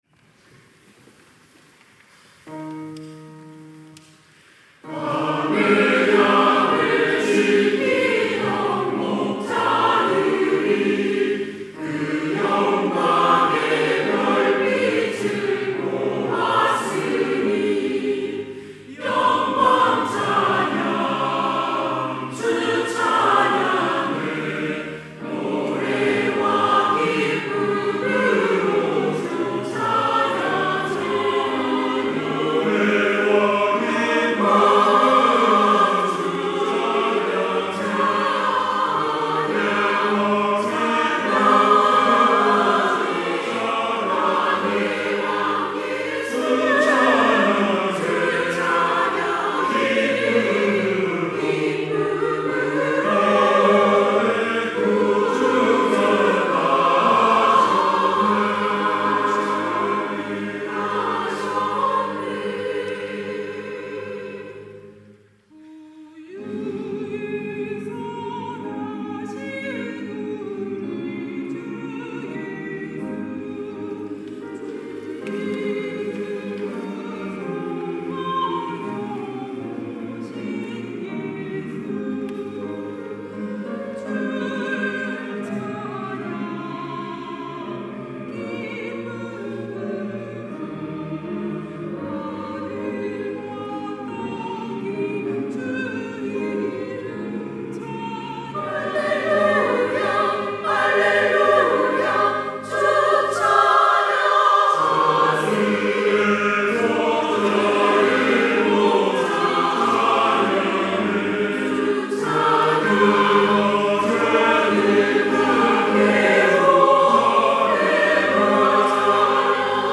시온(주일1부) - 밤에 양을 지키던 목자들이
찬양대